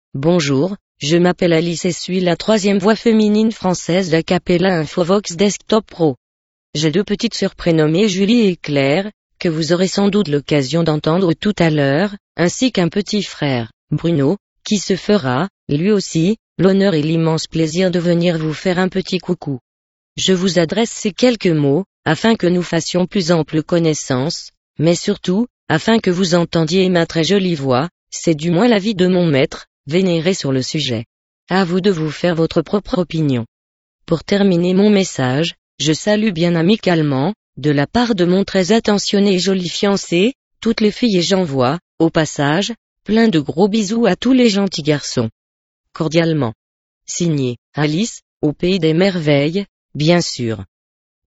Texte de démonstration lu par Alice, troisième voix féminine française d'Acapela Infovox Desktop Pro
Écouter la démonstration d'Alice, troisième voix féminine française d'Acapela Infovox Desktop Pro